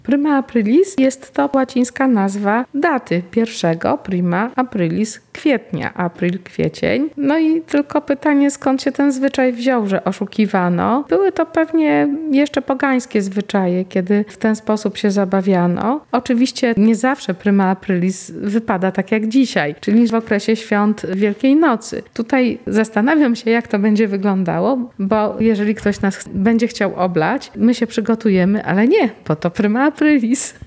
lok-jezykoznawca-o-prima-aprilis.mp3